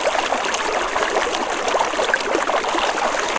fountain-2.ogg.mp3